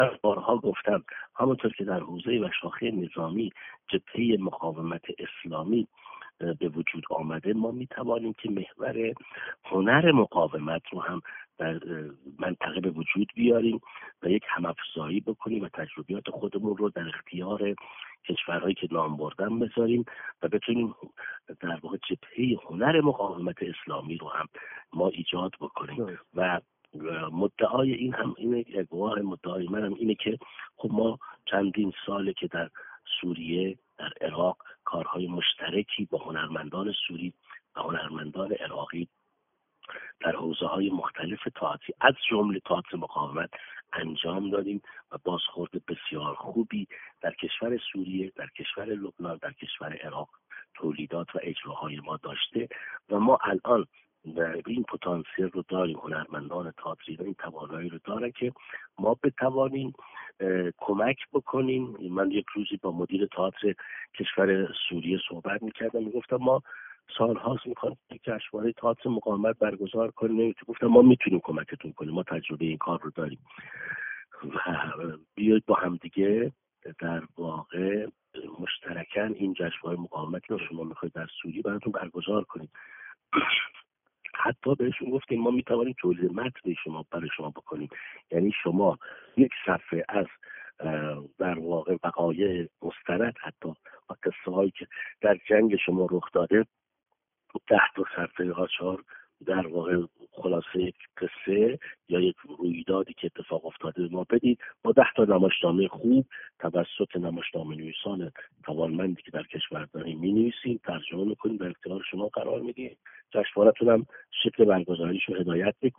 در گفت‌وگو با ایکنا: